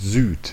Ääntäminen
Synonyymit Süden Südwind Mittag Ääntäminen Tuntematon aksentti: IPA: /zyːt/ Lyhenteet S Haettu sana löytyi näillä lähdekielillä: saksa Käännöksiä ei löytynyt valitulle kohdekielelle.